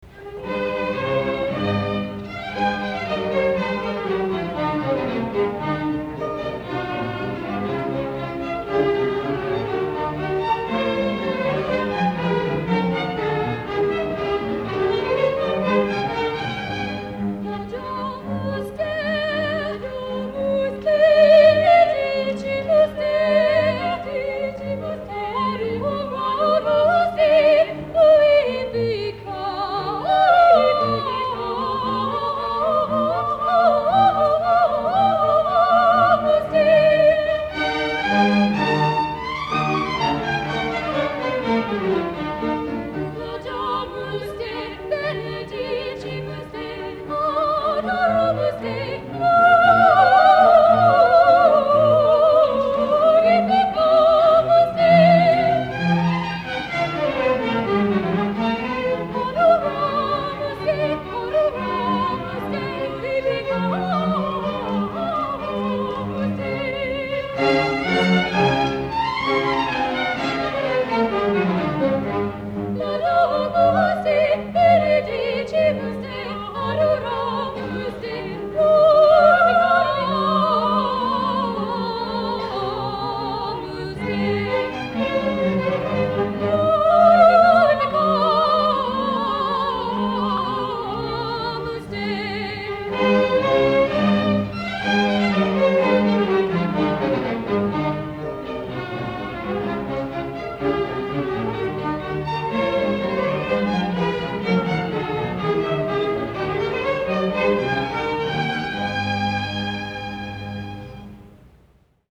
Christmas Concert 1972
Clay High Gym